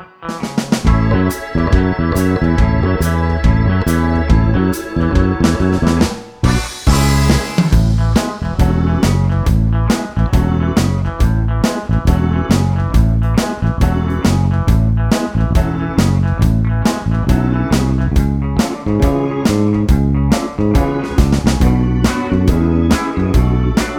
no Backing Vocals Soundtracks 3:16 Buy £1.50